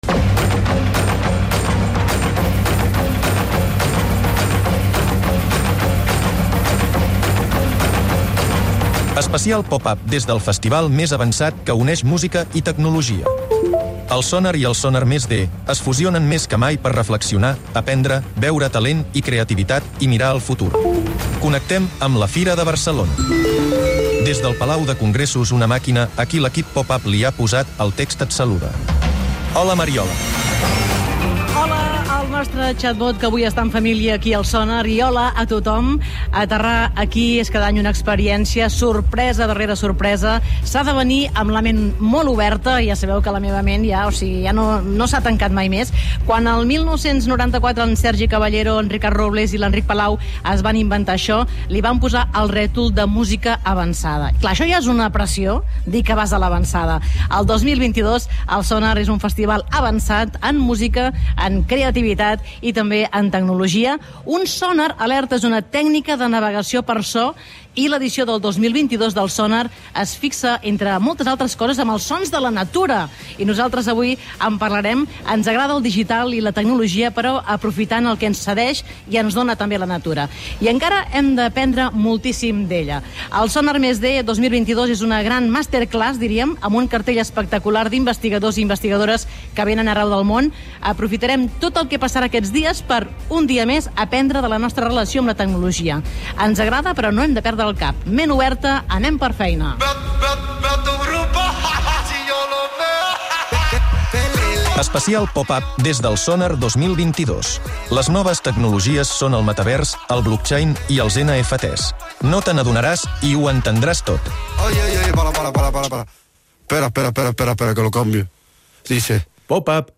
Programa especial en directe des del Sonar+D.